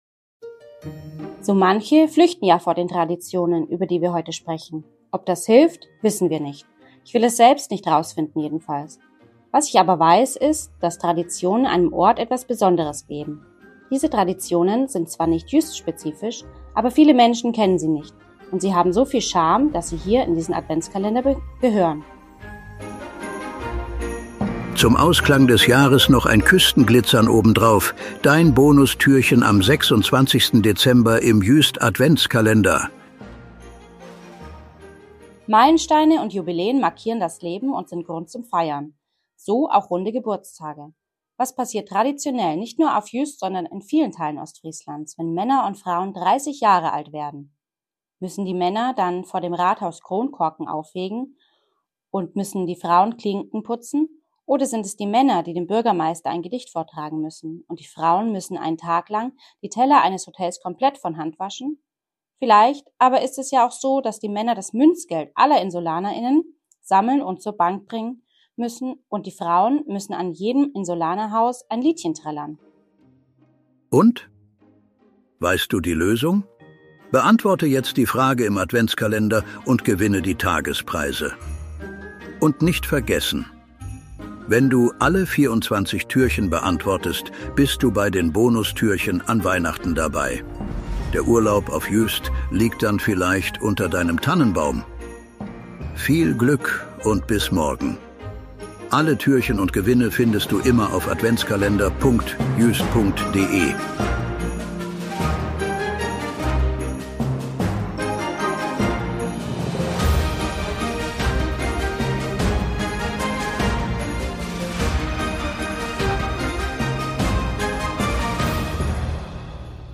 guten Geistern der Insel Juist, die sich am Mikro abwechseln und